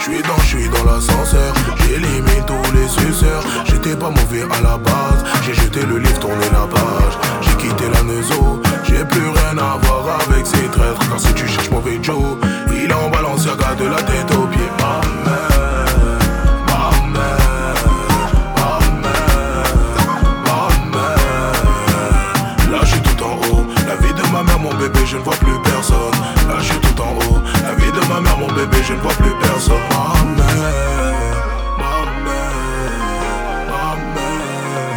Жанр: Хип-Хоп / Рэп
Afro-Beat, African, Hip-Hop, Rap